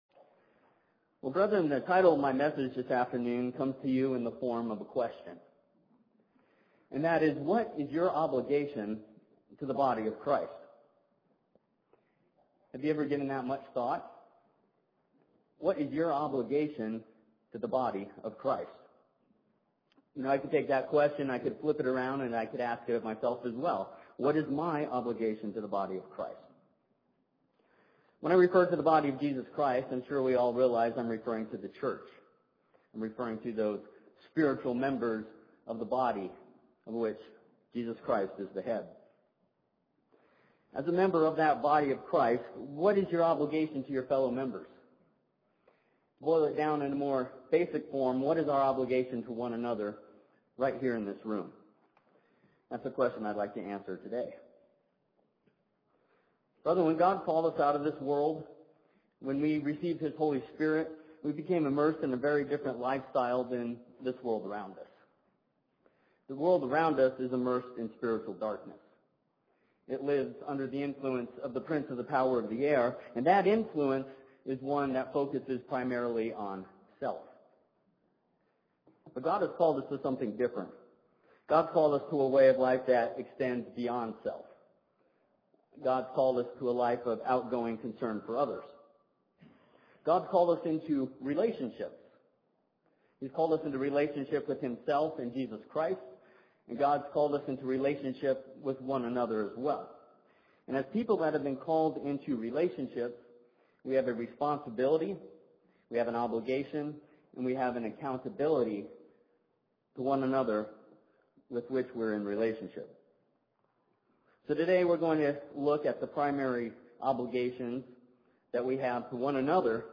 This sermon discusses several areas in which God expects us to help and show love for our fellow brethren.